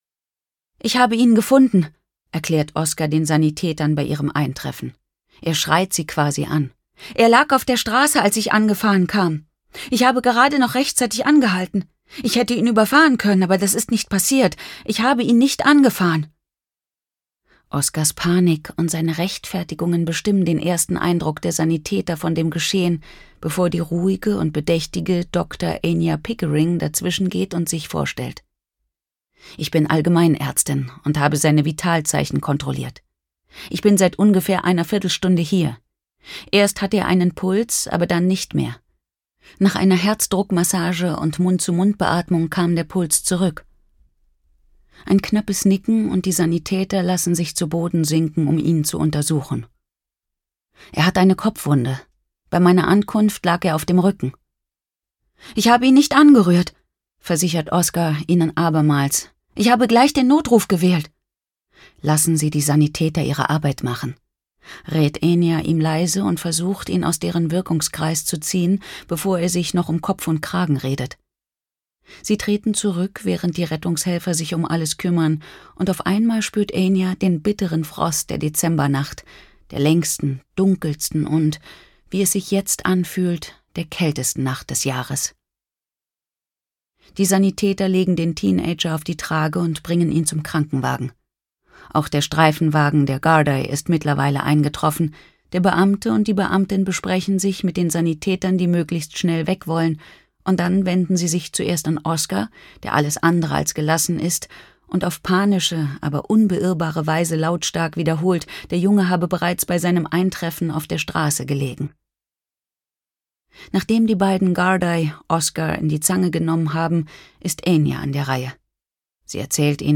Details zum Hörbuch